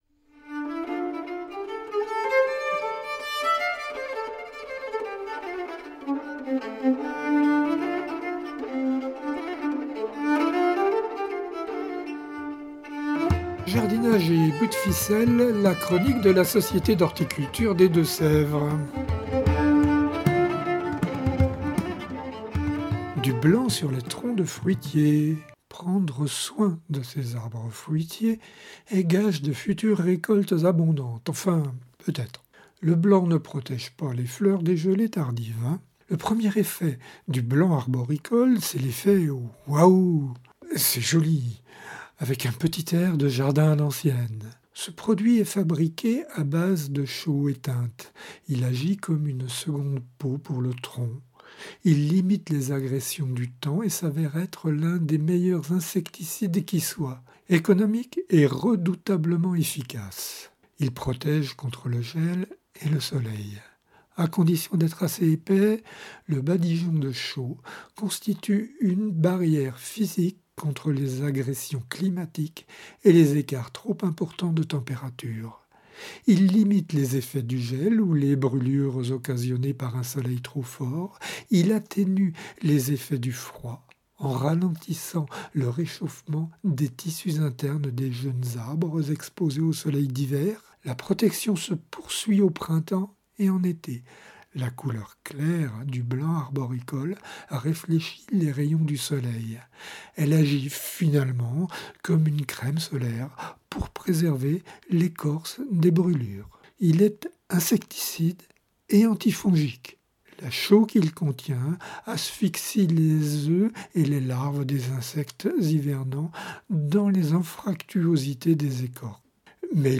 (Ces chroniques sont diffusées chaque semaine sur les radios D4B et Pigouille Radio)